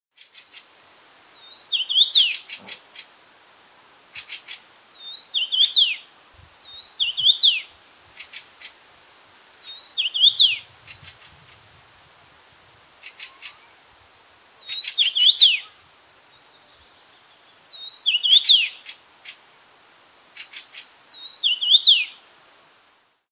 Common rosefinch song Rosenfink sang sound effects free download